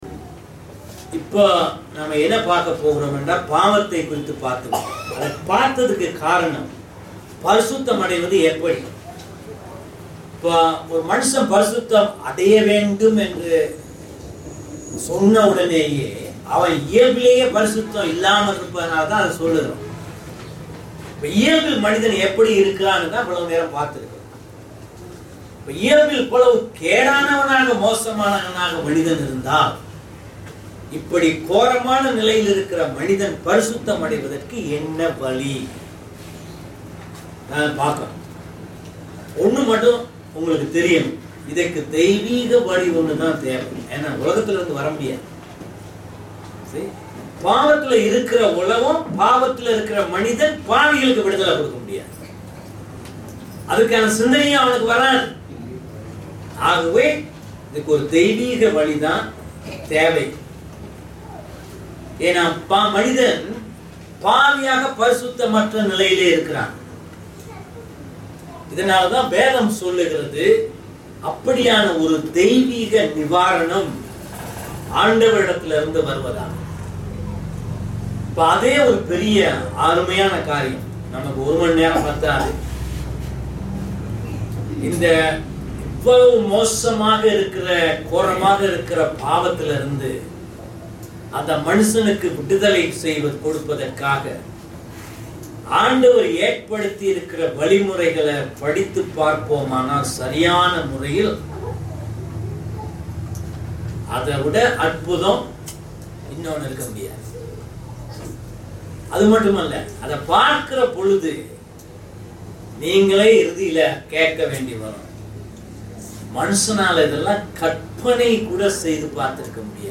கீழ்வரும் பிரசங்கங்களை ஒலி (Audio) வடிவில் கேட்கலாம் & பதிவிறக்கம் (Download) செய்துக்கொள்ளலாம்.